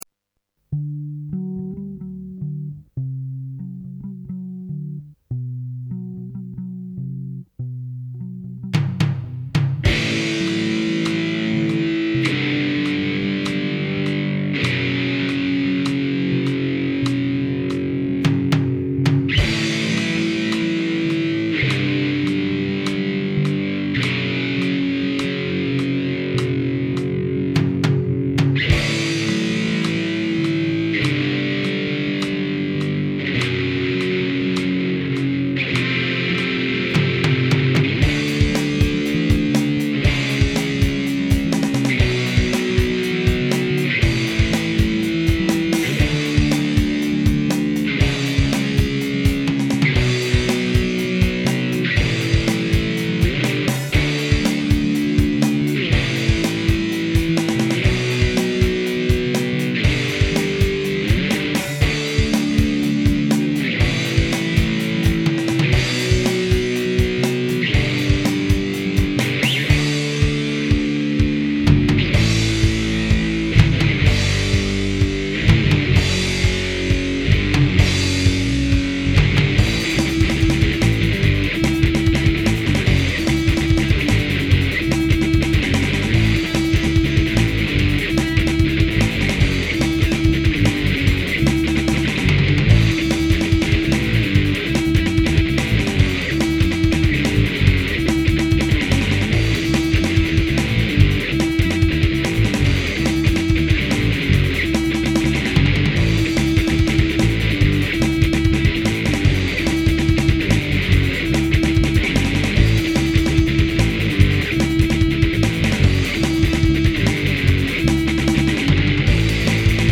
voz y guitarra
batería
punk